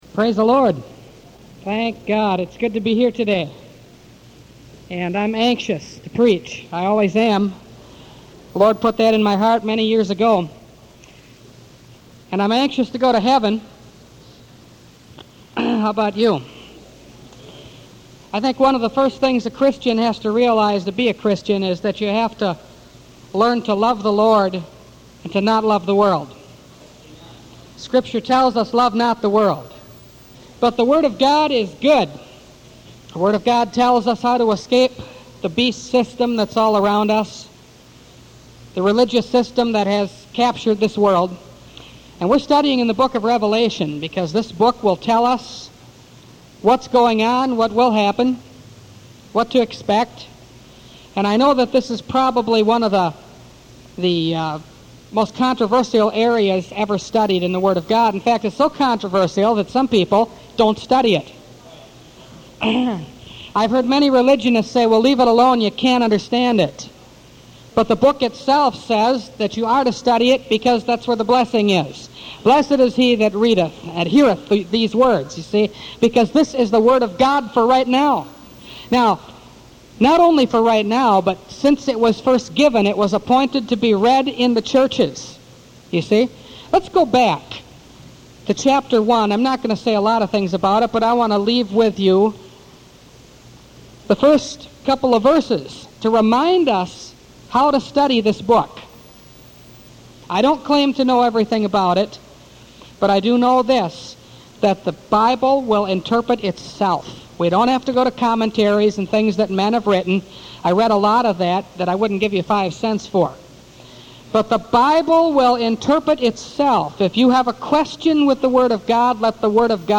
Revelation Series – Part 9 – Last Trumpet Ministries – Truth Tabernacle – Sermon Library